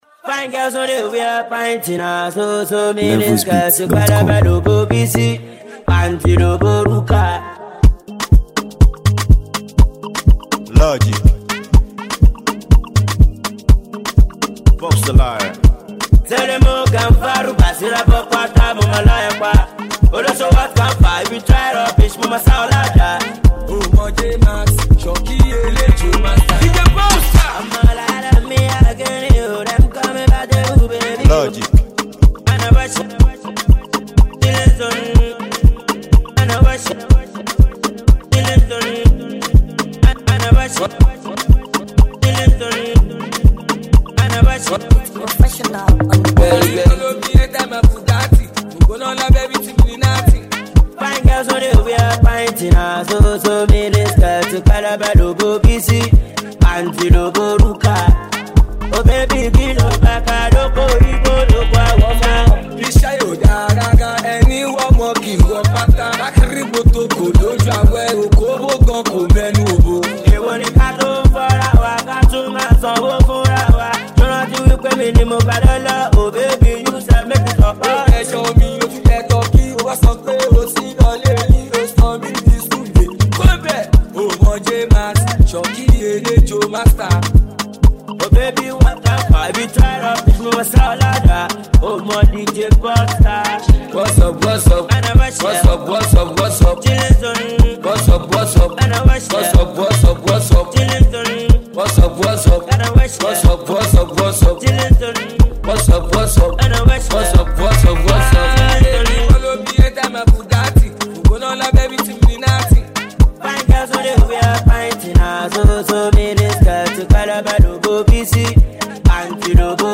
street-pop
street vibes, infectious rhythms, and party-ready energy